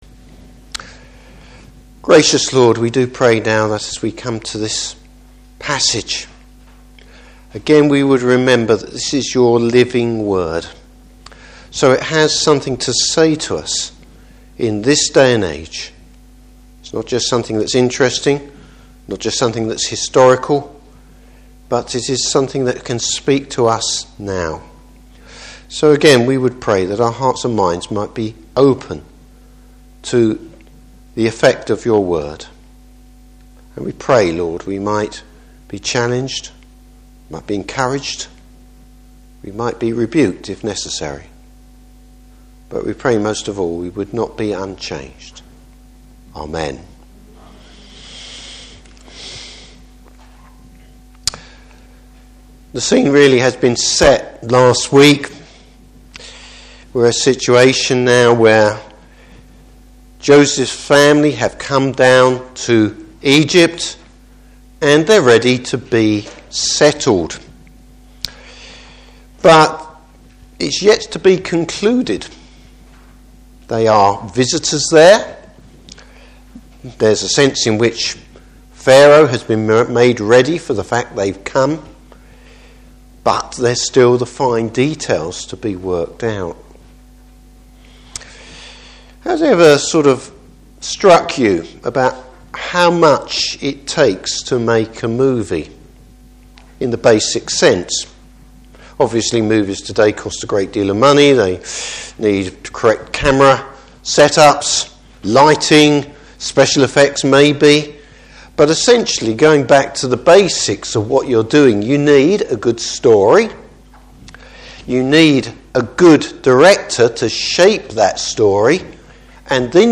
Service Type: Evening Service Joseph acts as an intercessor for his family.